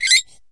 玻璃 陶瓷 " 陶瓷冰淇淋碗金属勺子在碗内发出刺耳的声音 04
描述：用金属勺刮擦陶瓷冰淇淋碗的内部。 用Tascam DR40录制。
Tag: 金属勺 尖叫 刮去 勺子 尖叫 陶瓷 金属